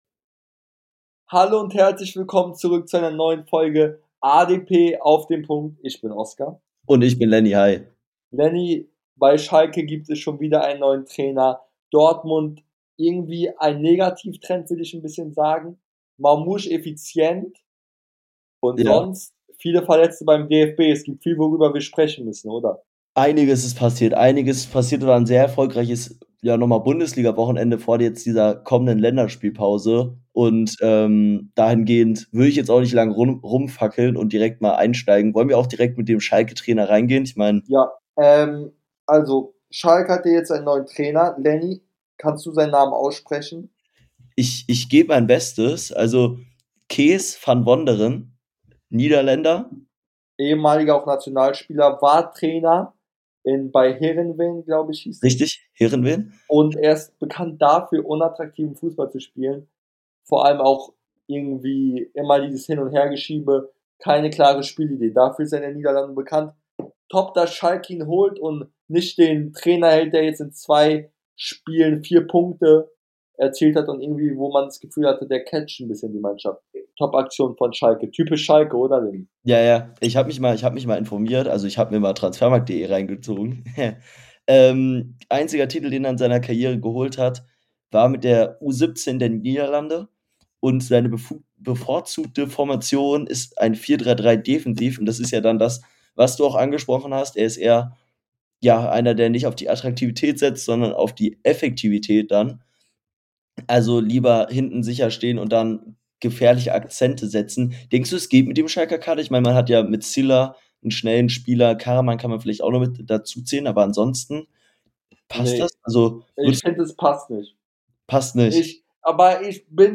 In der heutigen Folge reden die beiden Hosts über Schalkes Trainerwechsel,Dortmunds Niederlage , und den DFB Kader